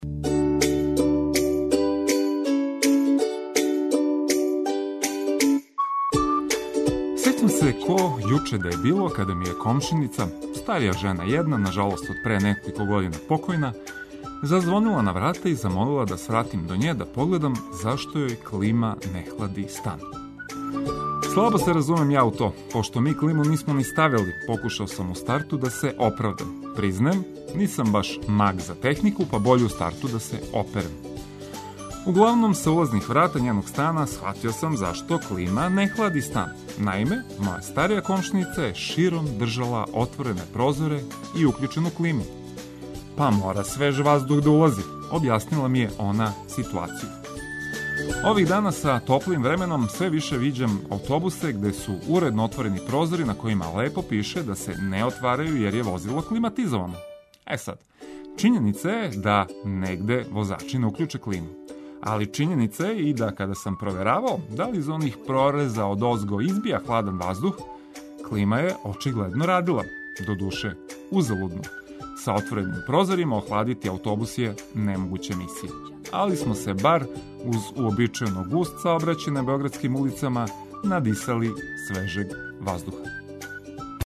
Јутро је лепше уз одлично расположене радио-пријатеље који ће вас пробудити уз много летњих нота, али и важних вести из земље и света.